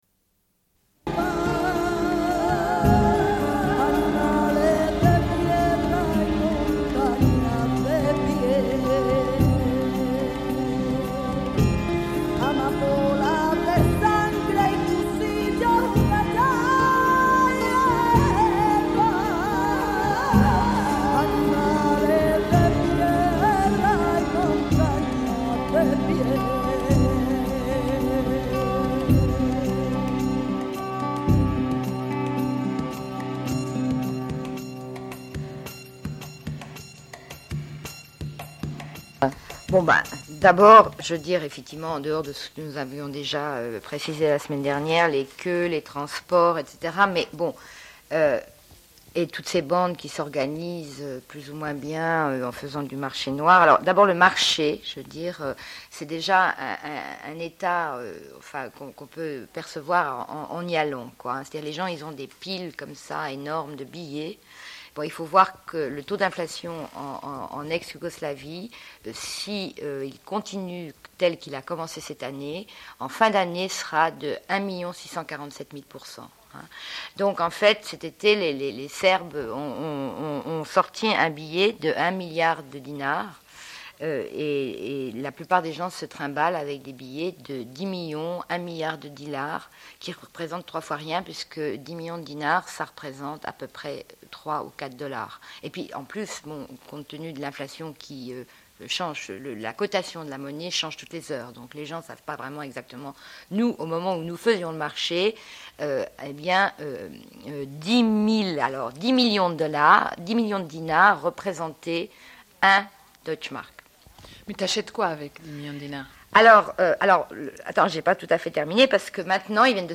Une cassette audio, face A31:32